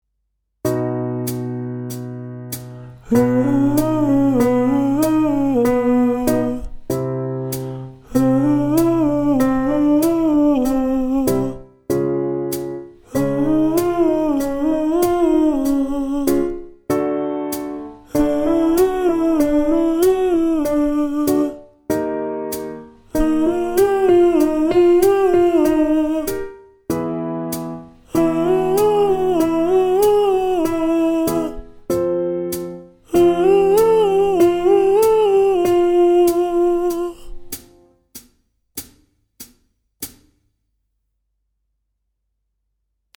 息漏れのある裏声は「ウ」の母音が出しやすい。
息漏れのある裏声　輪状甲状筋　CT
Ex.2　裏声トレーニング　男性声 (裏声A 　喉頭低め　ローラリンクス）
ボイトレ息漏れのある裏声　練習トラック1.mp3